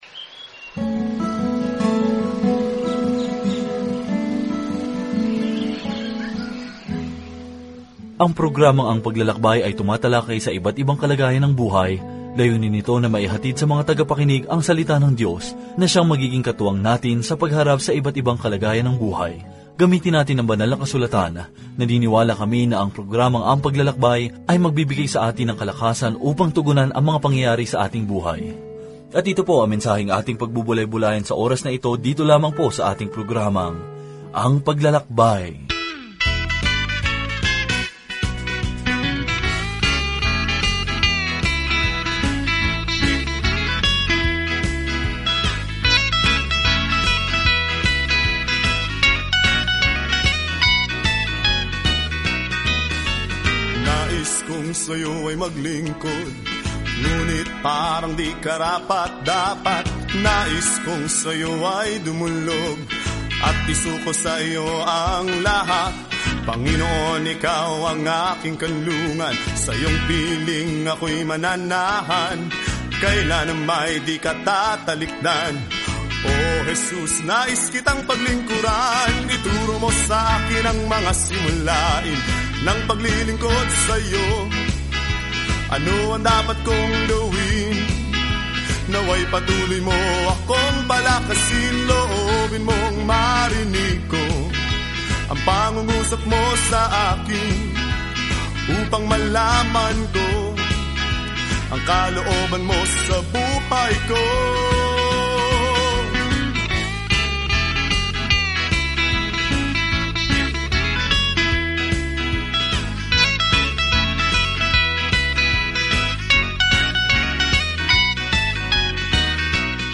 Banal na Kasulatan Mga Gawa 9:13-38 Araw 11 Umpisahan ang Gabay na Ito Araw 13 Tungkol sa Gabay na ito Ang gawain ni Jesus ay nagsimula sa mga Ebanghelyo ngayon ay nagpapatuloy sa pamamagitan ng kanyang Espiritu, habang ang simbahan ay itinanim at lumalago sa buong mundo. Araw-araw na paglalakbay sa Acts habang nakikinig ka sa audio study at nagbabasa ng mga piling talata mula sa salita ng Diyos.